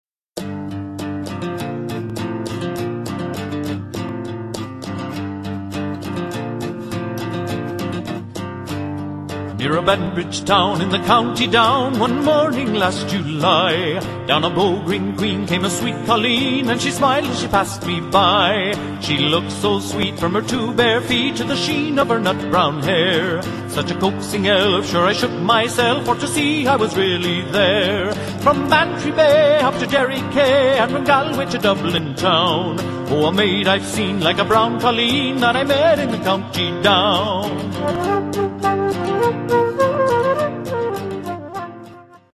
This San Diego Irish Band will melt your heart with soulful Celtic airs, and it will energize you with rousing Celtic dances. Serving San Diego and Southern California, the group performs on instruments that include guitar, flute, fiddle, bodhran, whistle, uilleann (Irish) bagpipes, and more; a vocalist is also available.
You'll enjoy the colorful, heart-rending, and exciting music and dance of the Celtic world through this San Diego Irish Band!